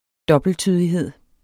Udtale [ ˈdʌbəlˌtyðiˌheðˀ ]